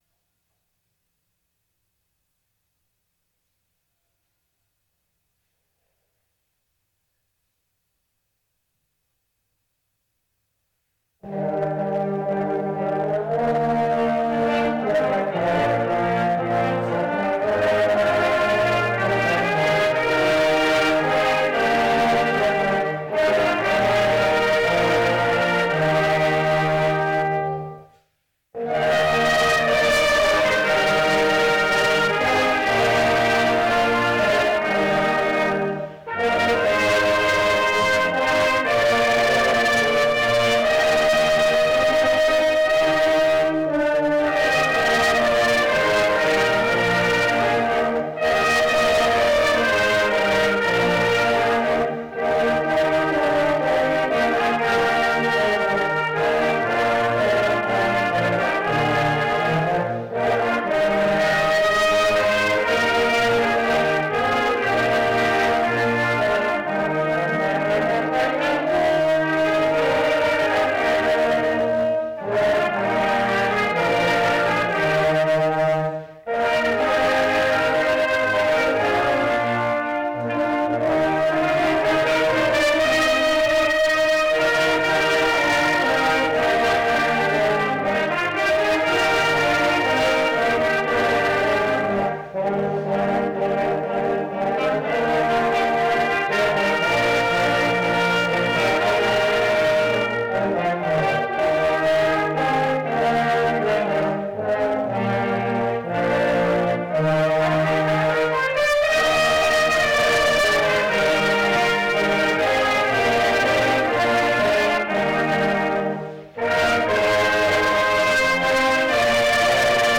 Gottesdienst - 25.12.2025 ~ Peter und Paul Gottesdienst-Podcast Podcast
Posaunenchor